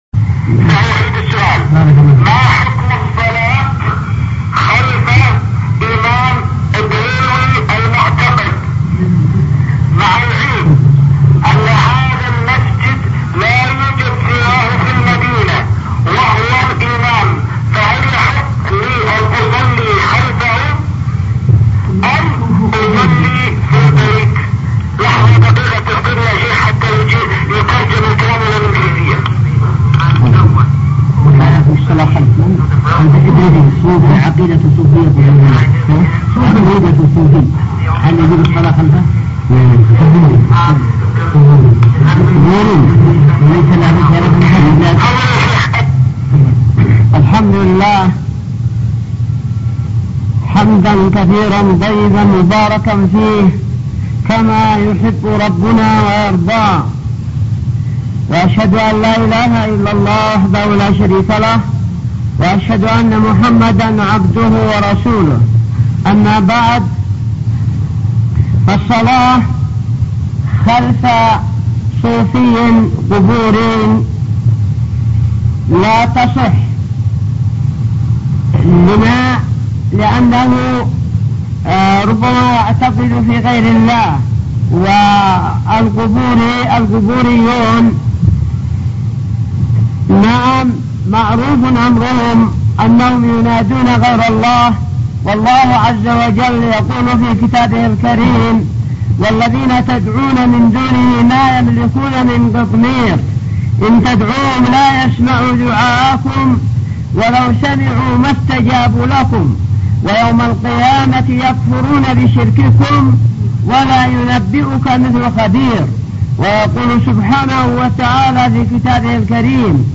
-------------- من شريط : ( أسئلة هاتفية من بريطانيا )